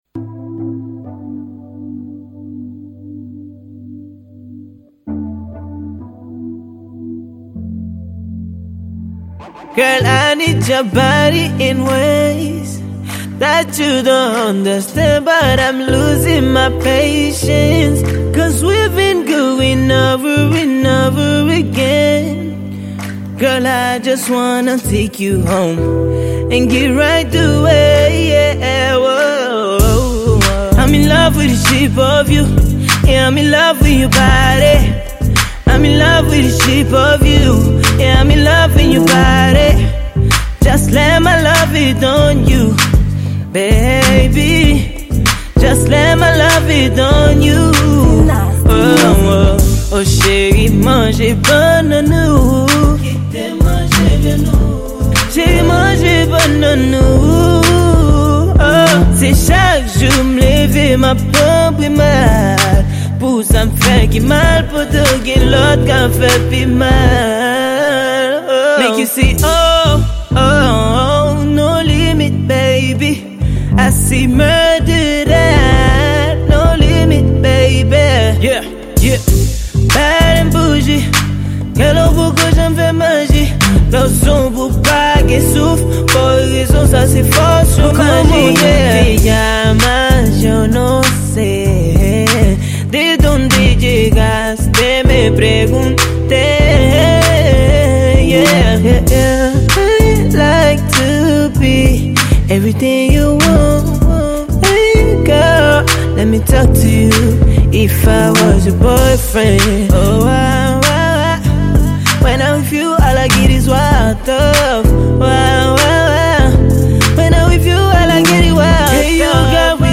Genre: Rnb.